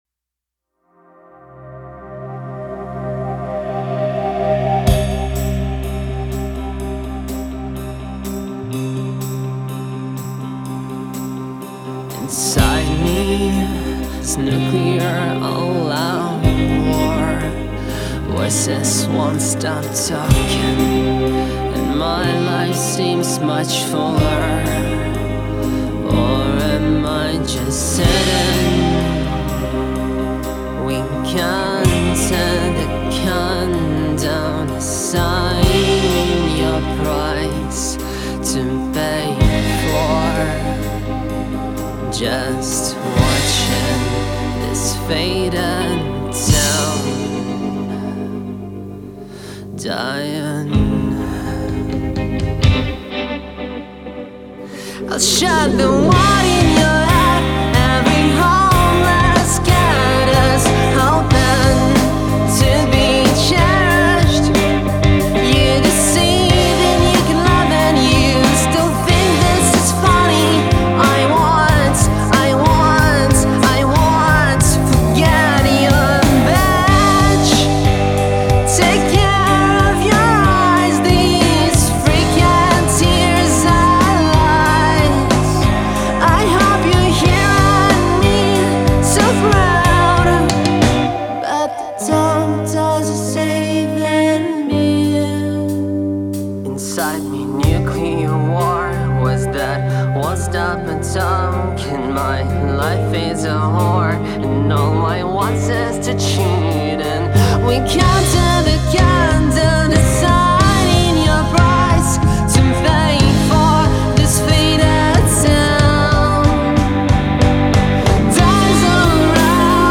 Барабаны неживые, все остальное живое.